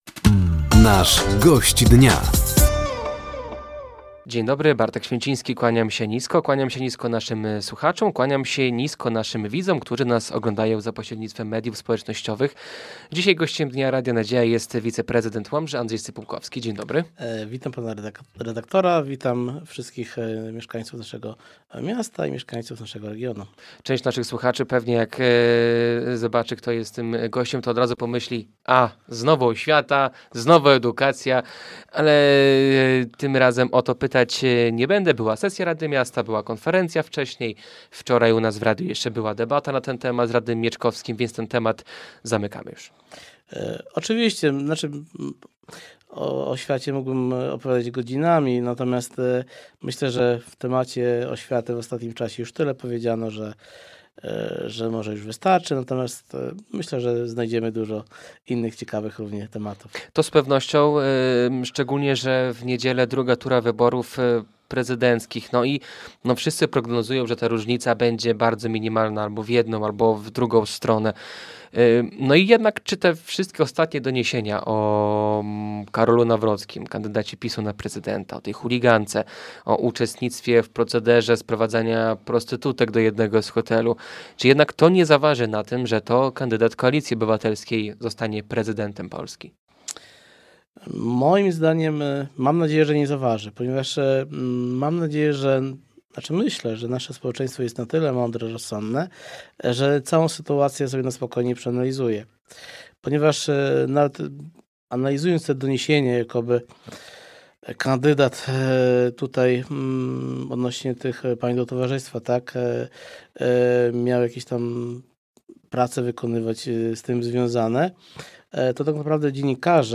Gościem Dnia Radia Nadzieja był wiceprezydent Łomży Andrzej Stypułkowski. Tematem rozmowy była II tura wyborów prezydenckich, rocznica kadencji samorządu oraz budżet obywatelski.